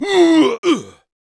monster / barbarian_boss / dead_1.wav
dead_1.wav